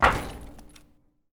metal_plate1.ogg